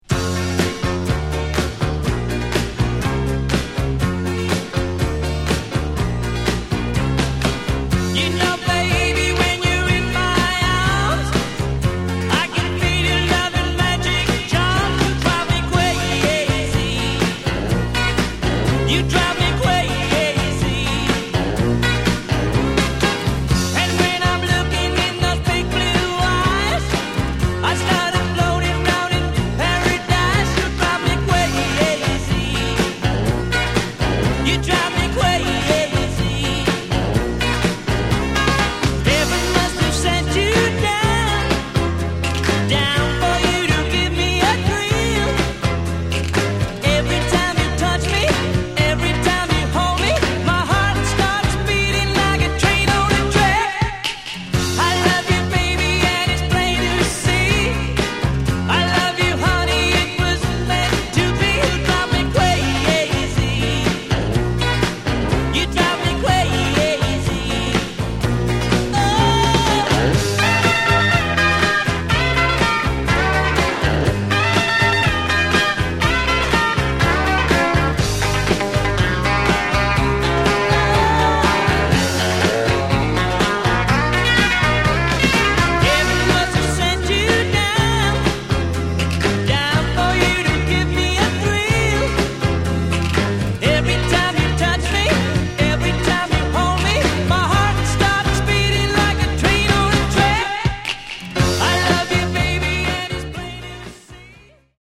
Genre: Rockabilly/Retro